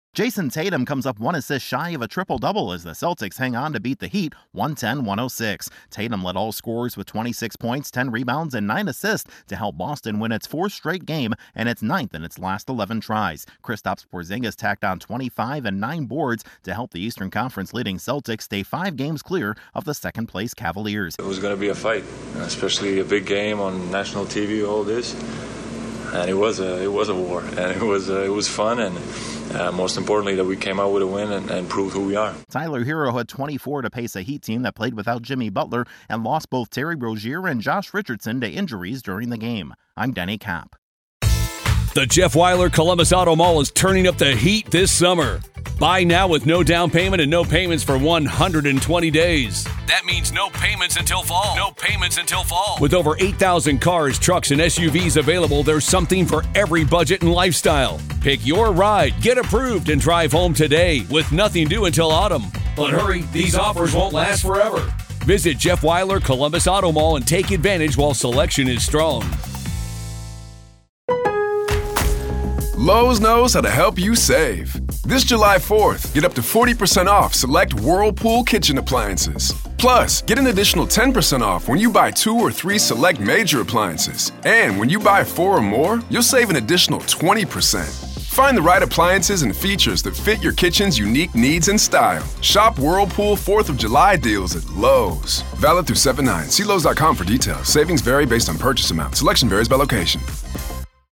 The Celtics get a strong performance from their All-Star forward. Correspondent